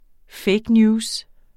Udtale [ ˈfεjg ˈnjuːs ]